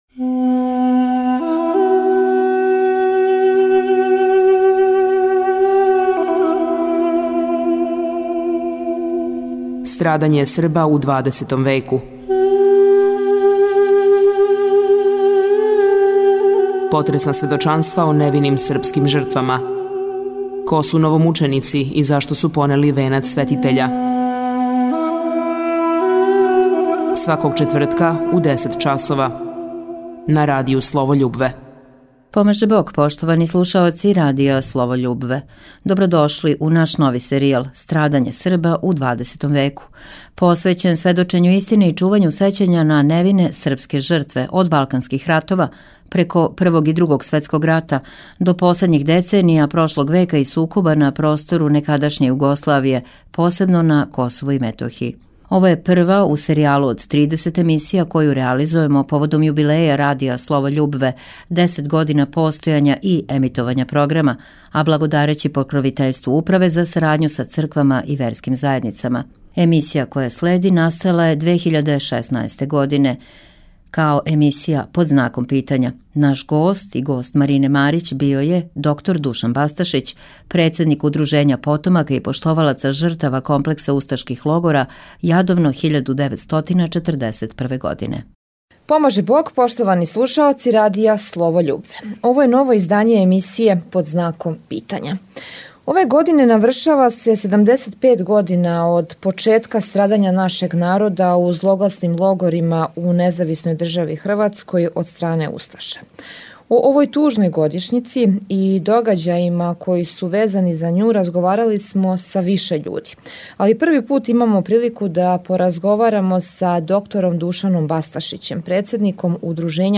Овај разговор из архиве Радија Слово љубве емитован је 2016. у оквиру емисије „Под знаком питања“, а поводом 75. године од почетка страдања нашег народа у злогласним логорима у НДХ од стране усташа.